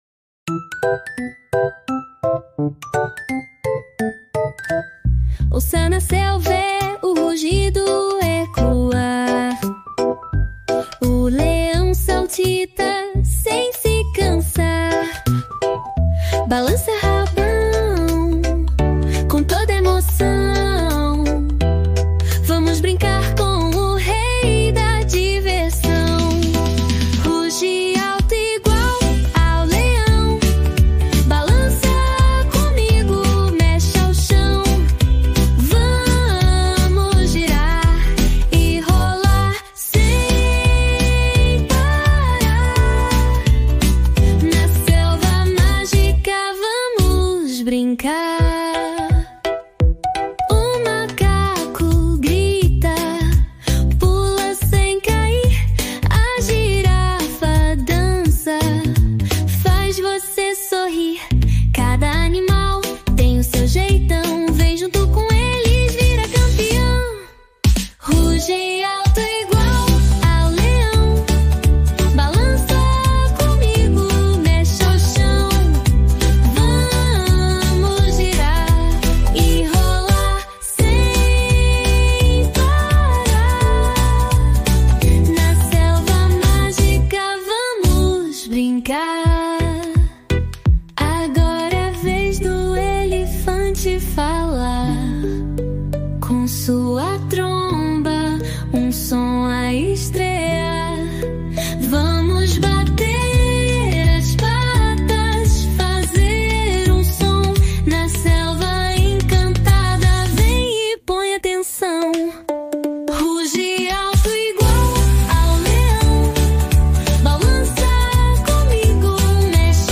Músicas Infantis